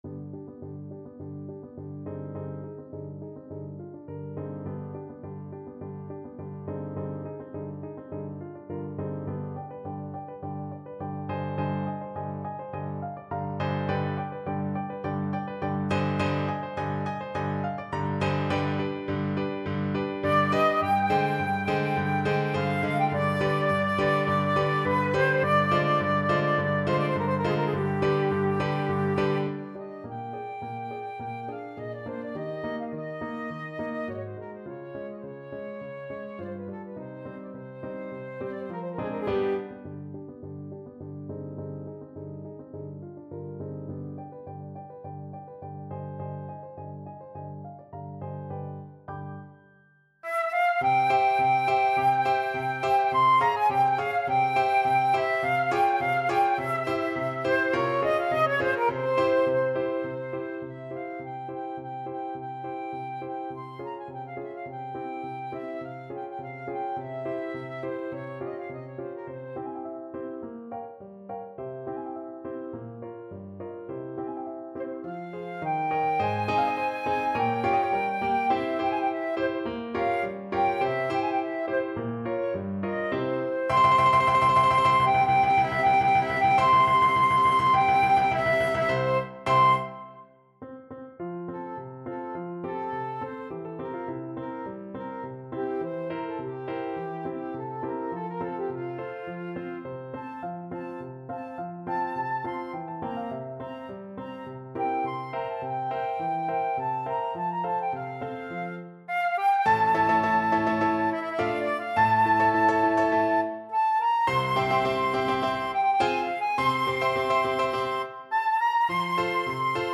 Paso doble =104
2/4 (View more 2/4 Music)
F5-F7
Classical (View more Classical Flute Music)